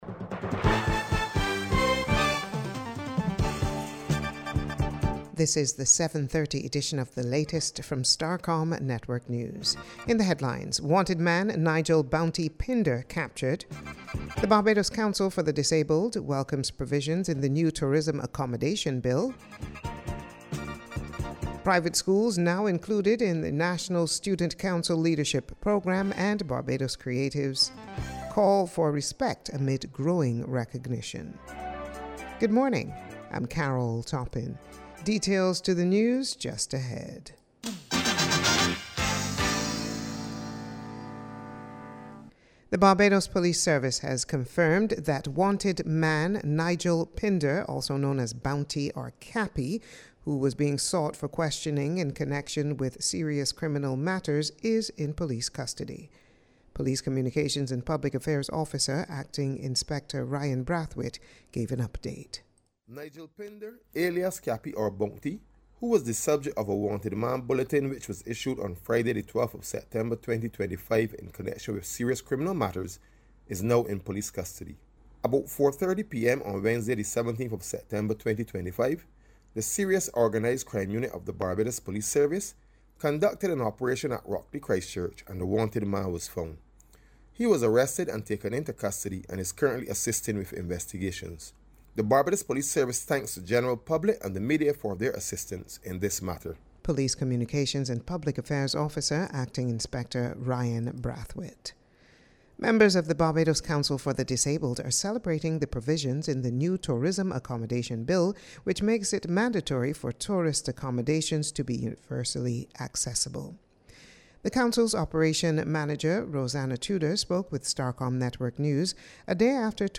This was disclosed by Central Bank Governor Cleviston Haynes this morning as he reviewed the country’s economic performance in 2019.